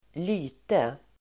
Ladda ner uttalet
Folkets service: lyte lyte substantiv, disability Uttal: [²l'y:te] Böjningar: lytet, lyten, lytena Synonymer: handikapp, missbildning Definition: handikapp Sammansättningar: lytes|komik (humour based on disabilities)